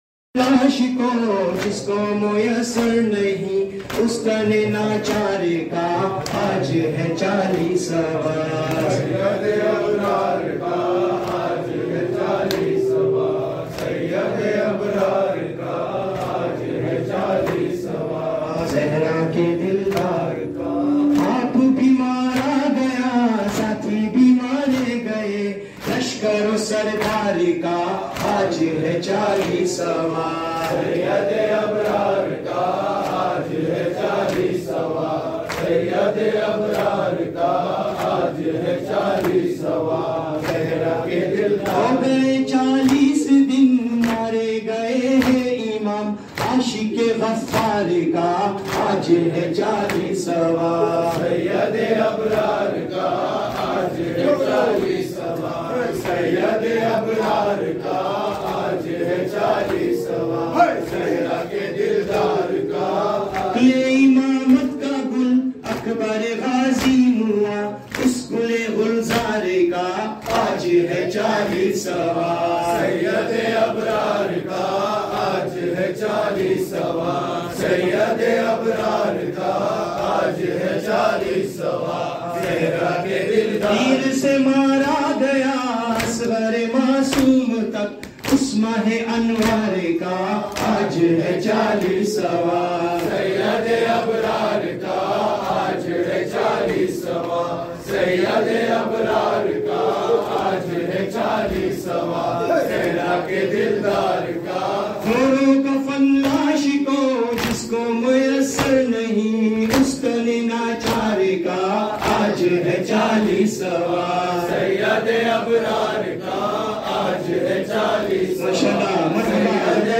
Chehlum / Arbaeen